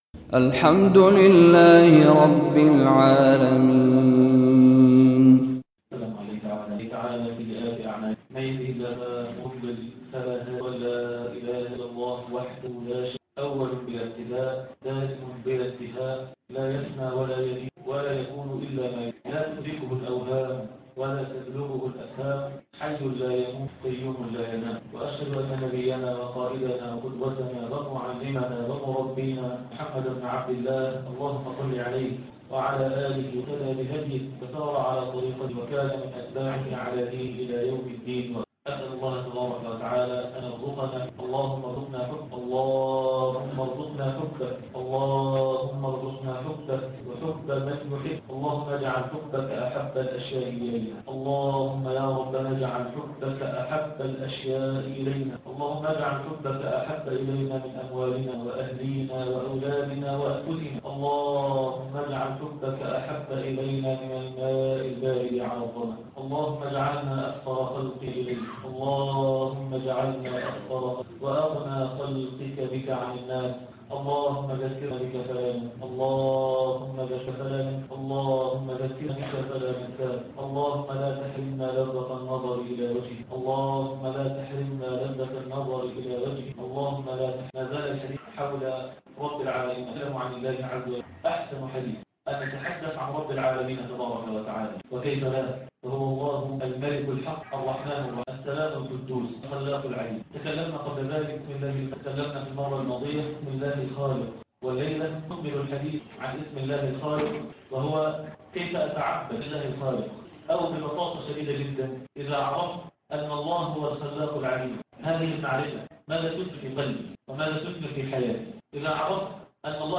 تفاصيل المادة عنوان المادة إنه ربي - الدرس الرابع تاريخ التحميل الأثنين 29 سبتمبر 2025 مـ حجم المادة 33.46 ميجا بايت عدد الزيارات 108 زيارة عدد مرات الحفظ 74 مرة إستماع المادة حفظ المادة اضف تعليقك أرسل لصديق